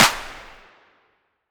• Reverb Hand Clap F Key 17.wav
Royality free hand clap - kick tuned to the F note. Loudest frequency: 3294Hz
reverb-hand-clap-f-key-17-WH4.wav